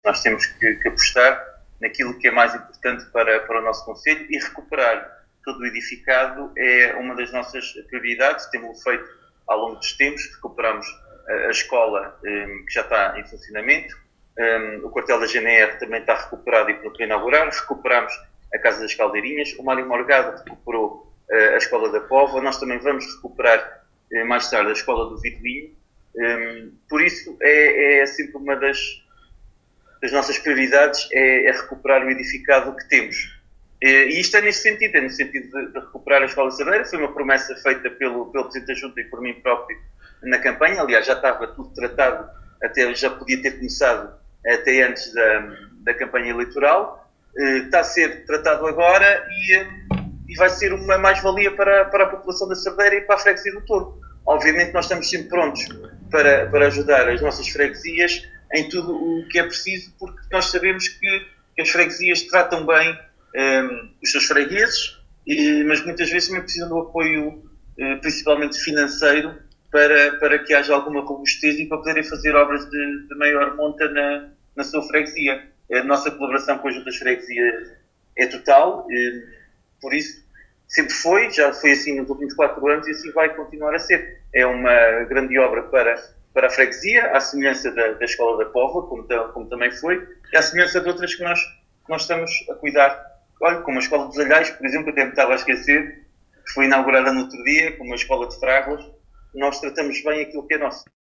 Paulo Marques, Presidente da Câmara, disse que esta obra vem de encontro ao plano de recuperação que está a ser feito no edificado do concelho.